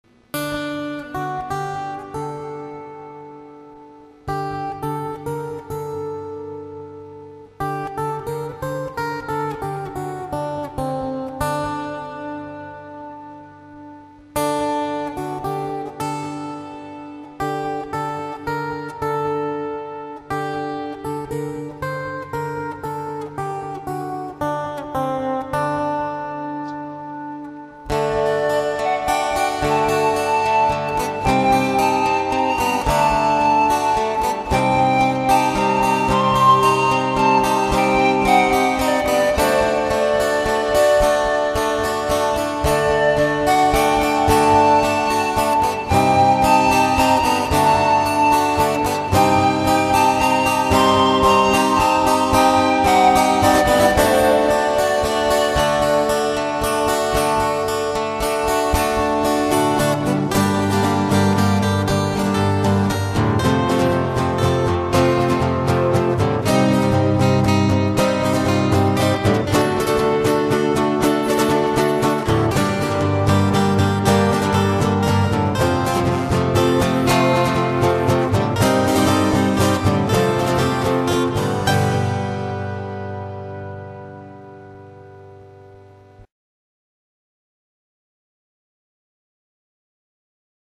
český renesanční country folk rock